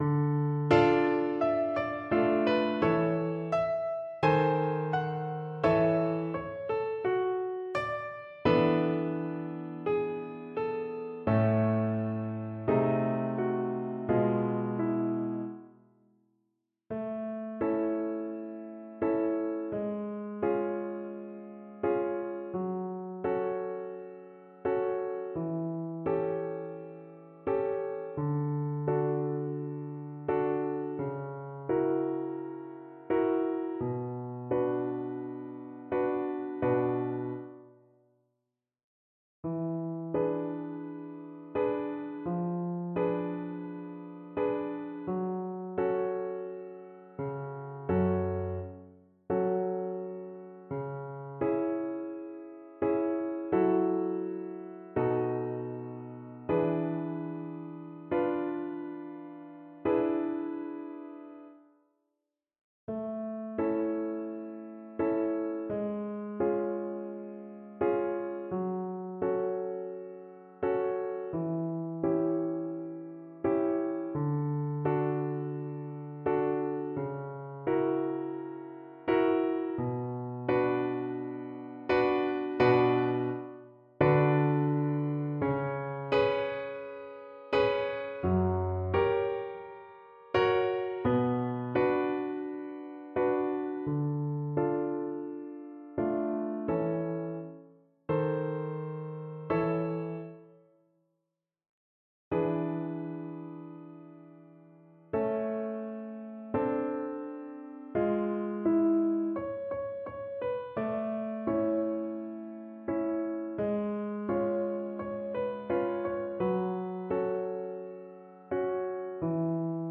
Slowly = c.60
2/4 (View more 2/4 Music)
Classical (View more Classical Violin Music)